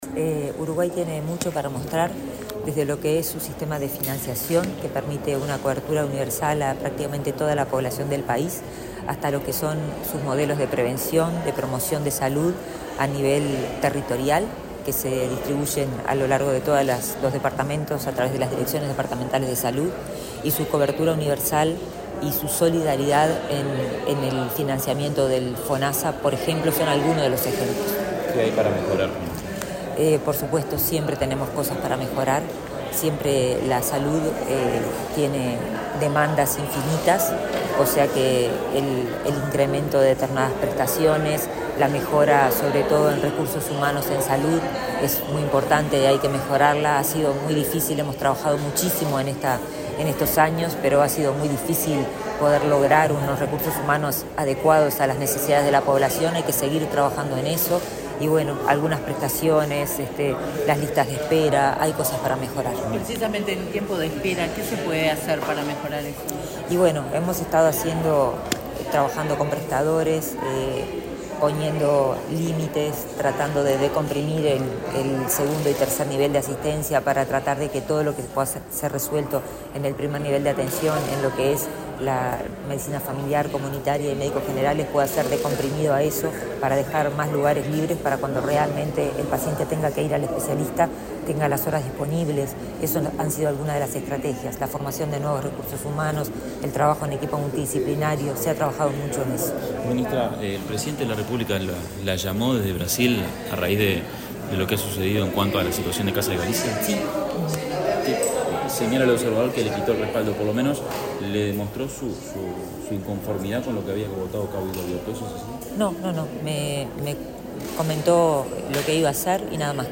Declaraciones de la ministra de Salud Pública, Karina Rando
Declaraciones de la ministra de Salud Pública, Karina Rando 07/12/2023 Compartir Facebook X Copiar enlace WhatsApp LinkedIn El prosecretario de la Presidencia, Rodrigo Ferrés, y la ministra de Salud Pública, Karina Rando, participaron, este jueves 7 en Torre Ejecutiva, del Foro Nacional de Atención Primaria en Salud. Luego, la secretaria de Estado dialogó con la prensa.